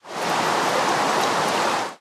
rain4.ogg